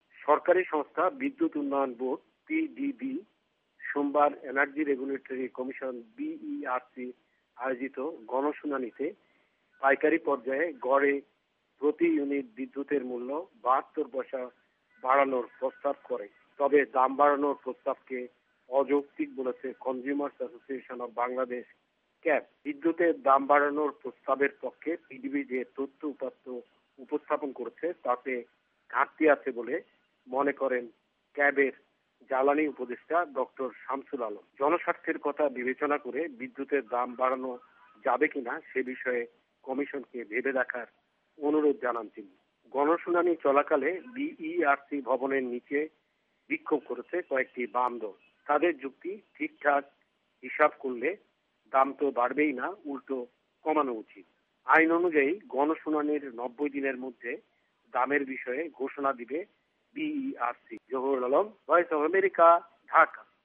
ঢাকা থেকে
রিপোর্ট